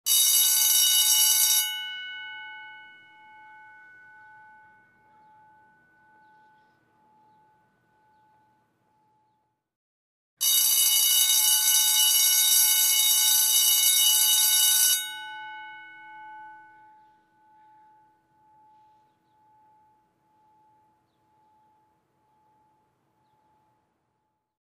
Звуки школьной перемены
Школьный звонок на перемену звук